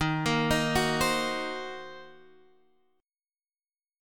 D# Minor 6th